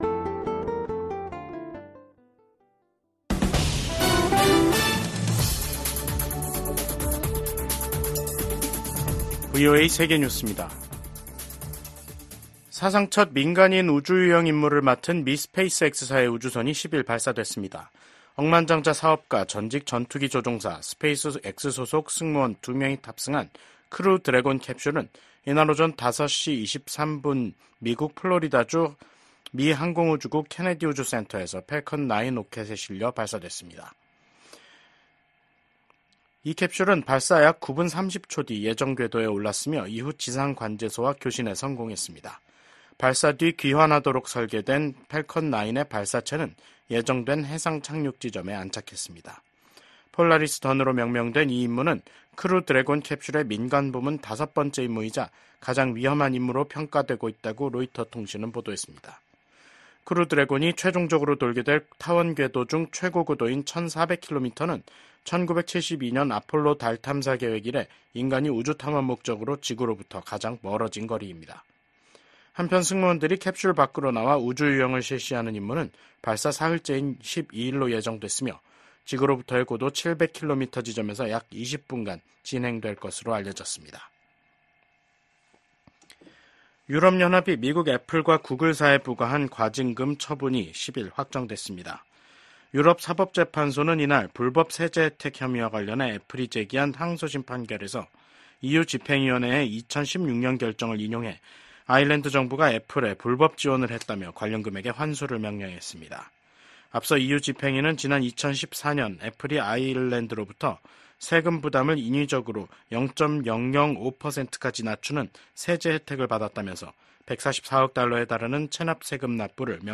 VOA 한국어 간판 뉴스 프로그램 '뉴스 투데이', 2024년 9월 10일 3부 방송입니다. 민주당 대통령 후보인 카멀라 해리스 부통령이 당선되면 동맹을 강화하며 국제 지도력을 발휘할 것이라는 입장을 재확인했습니다. 북한 사립대학 외국인 교수진의 복귀 소식에 미국 국무부는 미국인의 ‘북한 여행 금지’ 규정을 상기했습니다. 김정은 북한 국무위원장이 9.9절을 맞아 미국의 핵 위협을 주장하며 자신들의 핵 무력을 한계 없이 늘려나가겠다고 밝혔습니다.